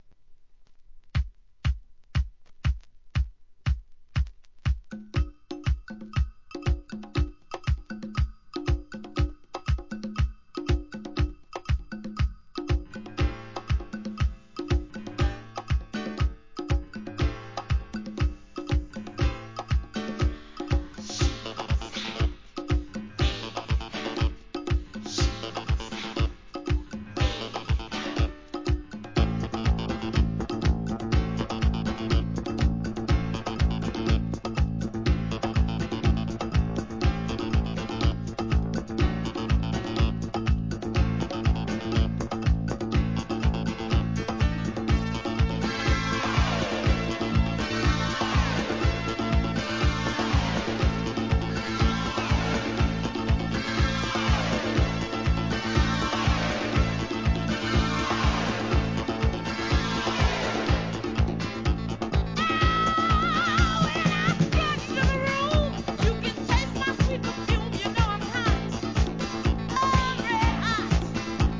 SOUL/FUNK/etc...
軽快DISCO満載!